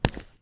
concrete_step_2_-03.wav